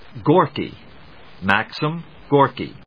音節Gor・ki, Gor・ky 発音記号・読み方/gˈɔɚkigˈɔː‐/発音を聞く